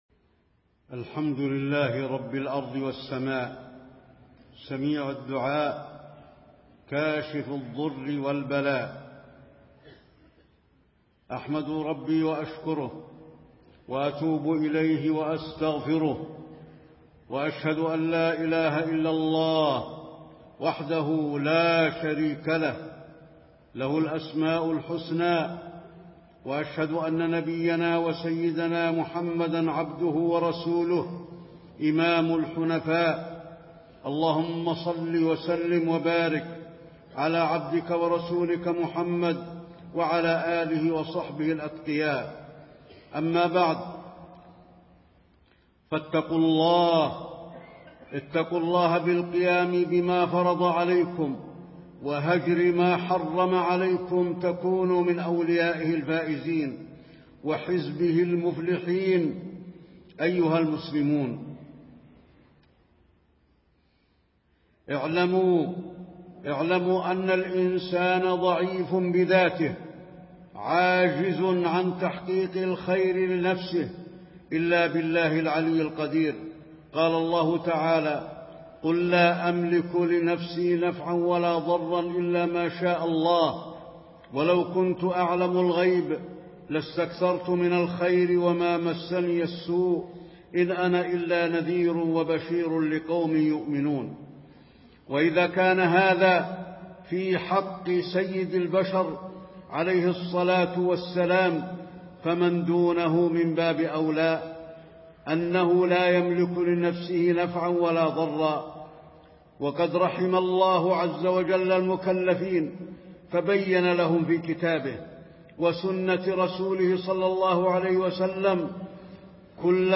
تاريخ النشر ٩ شوال ١٤٣٤ هـ المكان: المسجد النبوي الشيخ: فضيلة الشيخ د. علي بن عبدالرحمن الحذيفي فضيلة الشيخ د. علي بن عبدالرحمن الحذيفي أهمية الدعاء